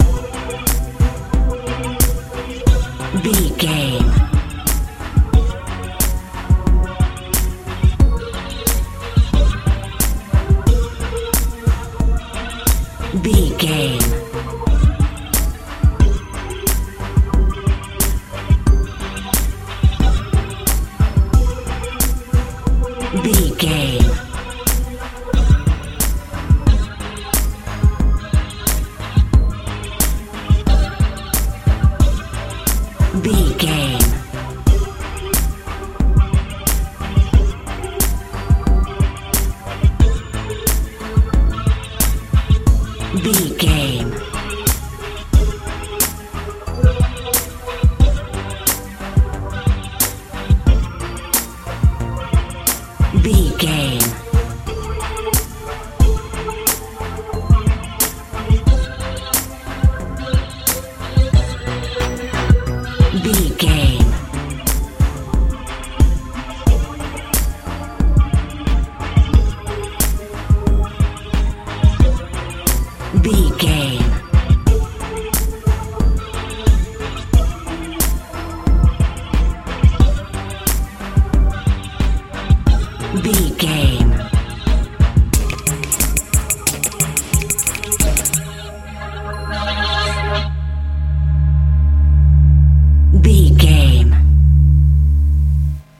modern dance
Ionian/Major
strange
mystical
synthesiser
bass guitar
drums
magical
dreamy
ethereal
futuristic